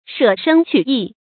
shě shēng qǔ yì
舍生取义发音
成语正音 舍，不能读作“shè”。